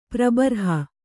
♪ prabarha